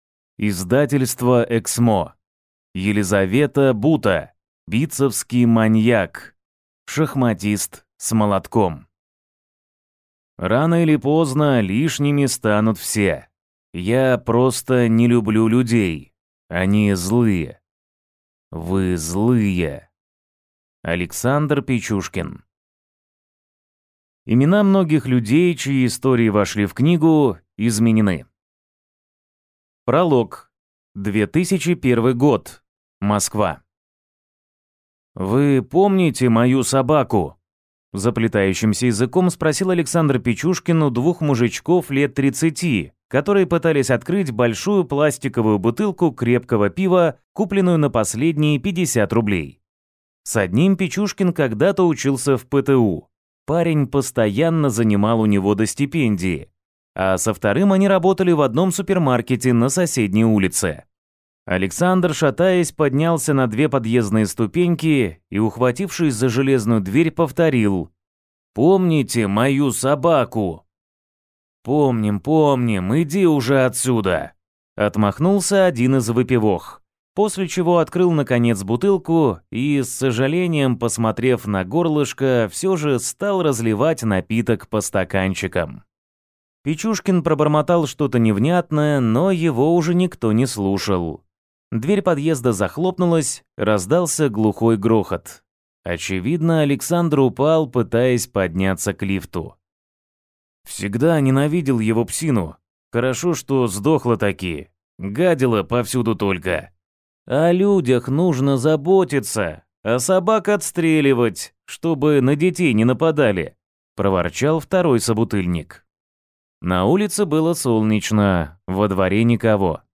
Аудиокнига Битцевский маньяк. Шахматист с молотком | Библиотека аудиокниг